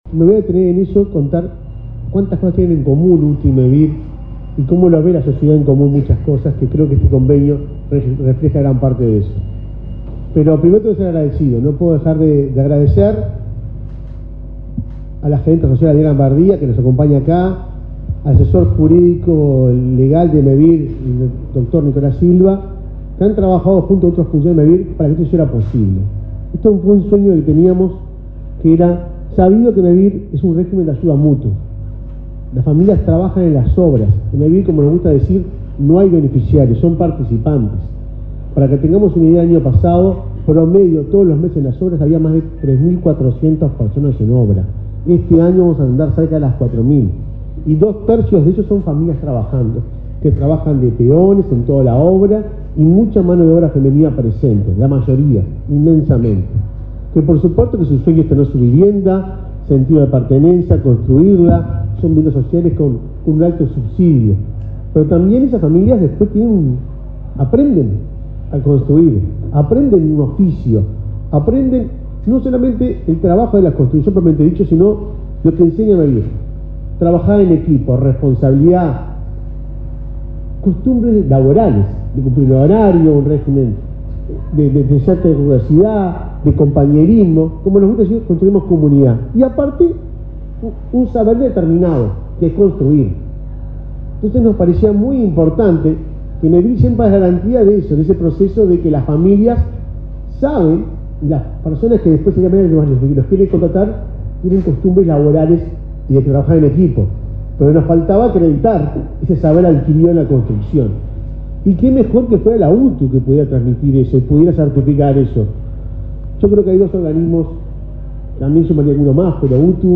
Palabra de autoridades en firma de convenio entre Mevir y UTU